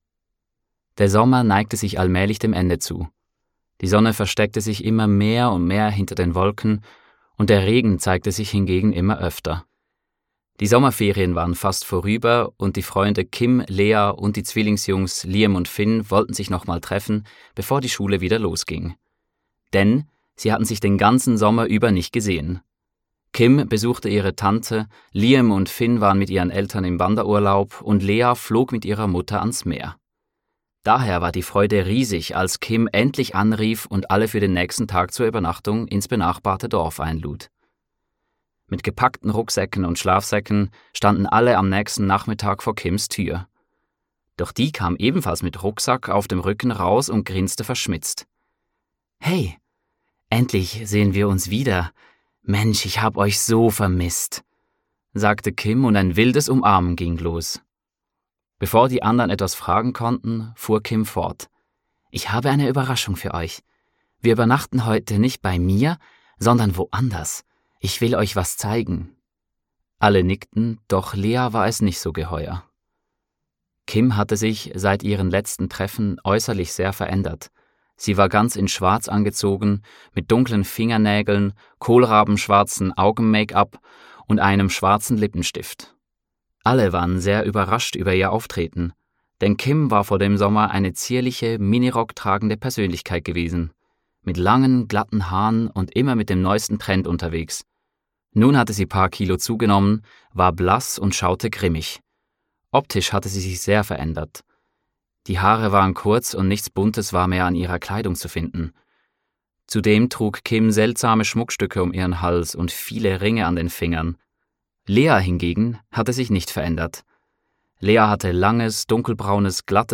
Hörbuch „Witchcraft – Der Weg zur Magie“